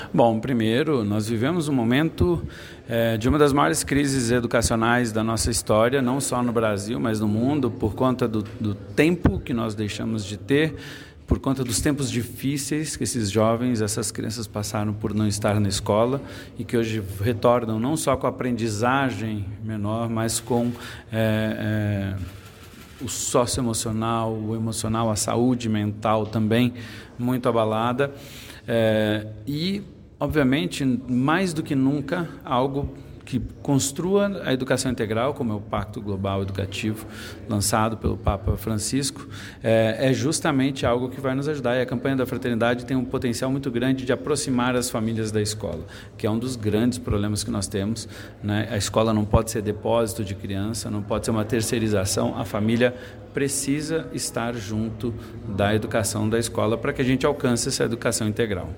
Em entrevista ao portal diocesano o secretário falou sobre a importância da Campanha para a conjuntura de ensino do Brasil: